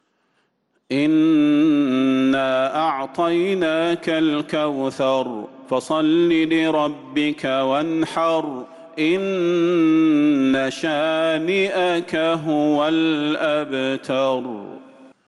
سورة الكوثر | رجب 1447هـ > السور المكتملة للشيخ صلاح البدير من الحرم النبوي 🕌 > السور المكتملة 🕌 > المزيد - تلاوات الحرمين